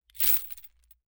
Metal_38.wav